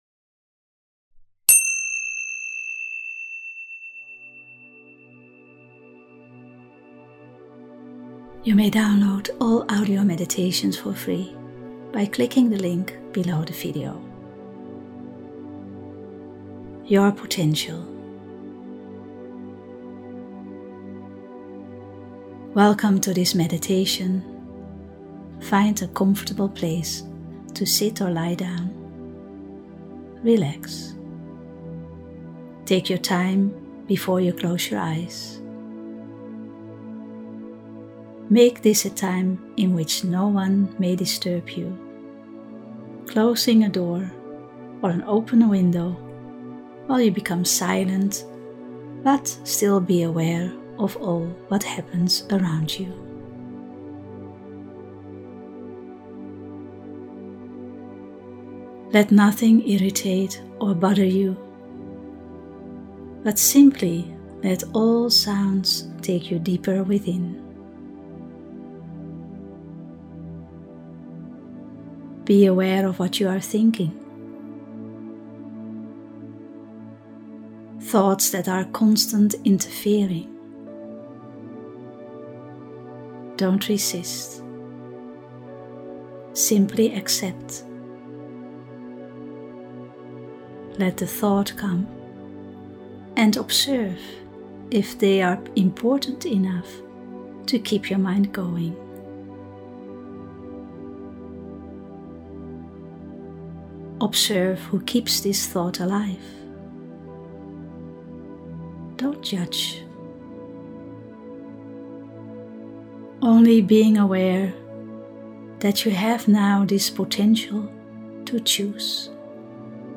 Meditation “Your potential”